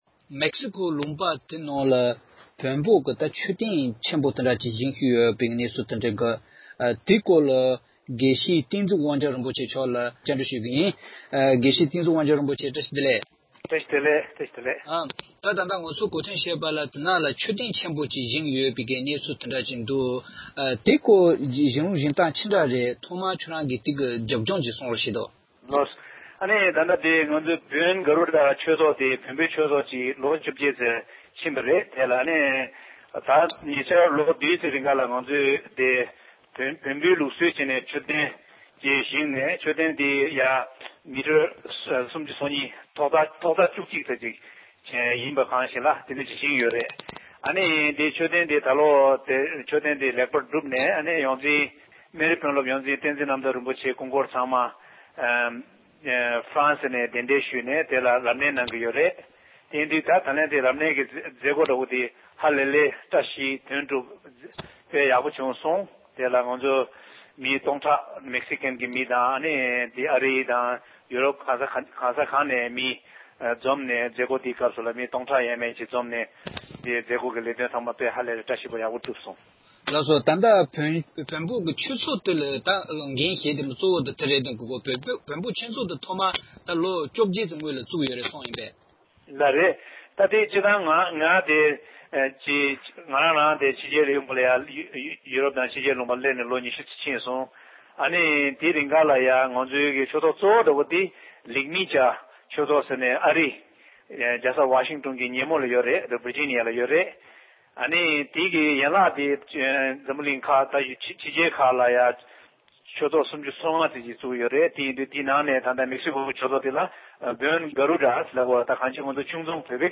མེཀ་སི་ཀོའི་ནང་བོད་ལུགས་ལྟར་མཆོད་རྟེན་ཆེན་པོ་ཞིག་བཞེངས་ཡོད་པའི་སྐོར་གླེང་མོལ།